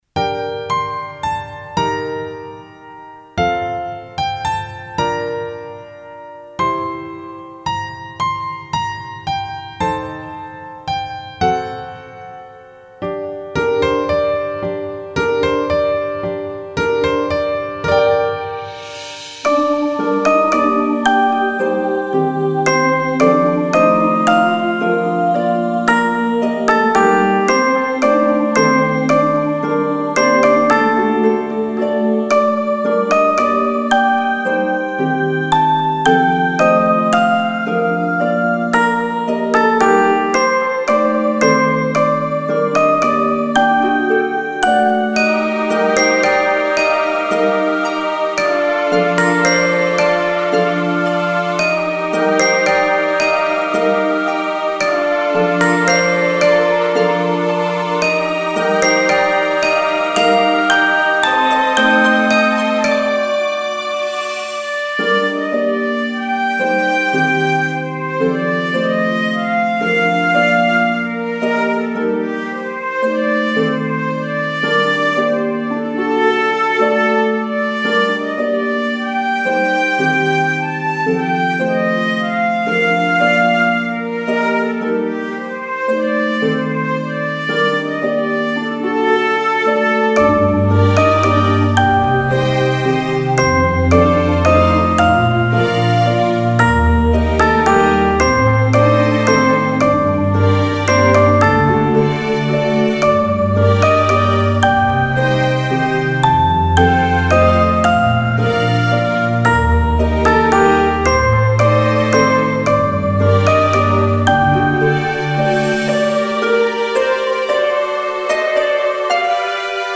フリーBGM イベントシーン 幻想的・神秘的
フェードアウト版のmp3を、こちらのページにて無料で配布しています。